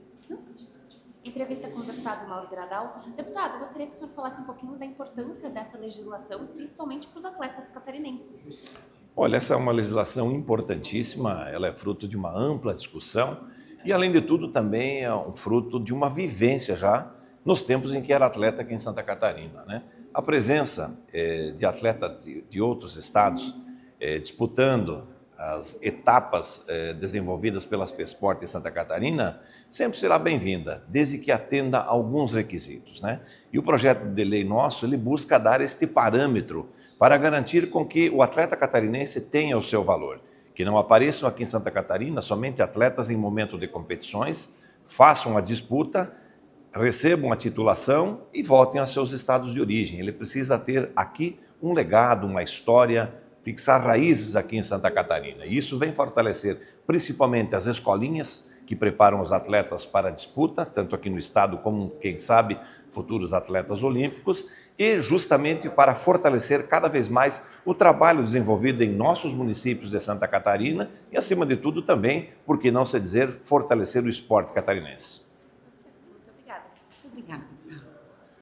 Entrevista.mp3